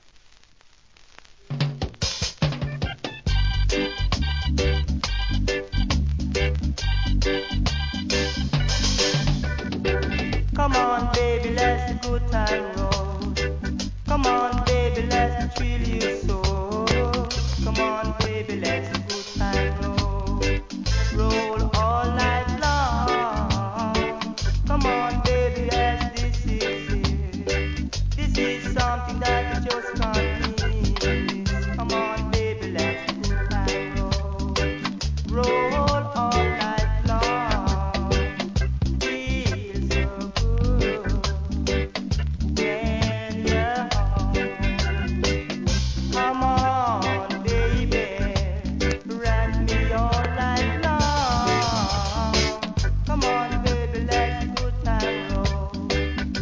REGGAE
GOOD VOCAL MINOR REGGAE!!